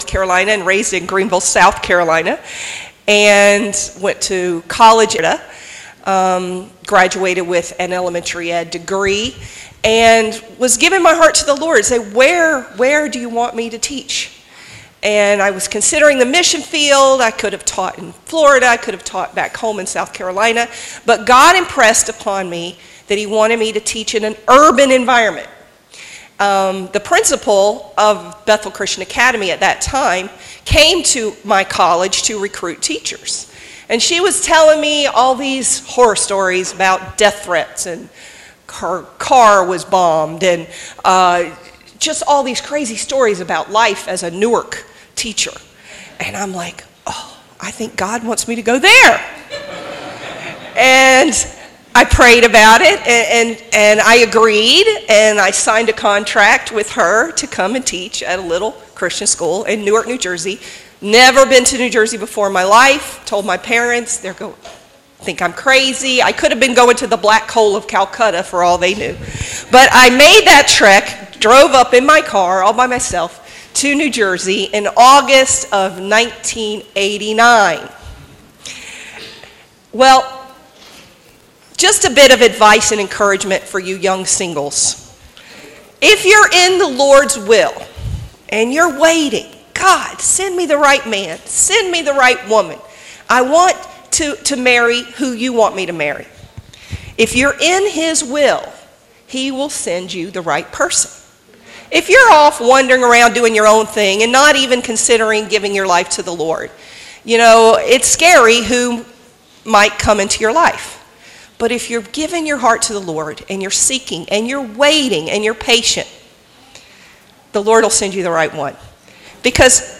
An inspiring message from our invited speaker